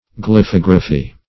Search Result for " glyphography" : The Collaborative International Dictionary of English v.0.48: Glyphography \Gly*phog"ra*phy\, n. [Gr.